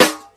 Snare (IGOR_S THEME)(1).wav